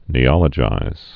(nē-ŏlə-jīz)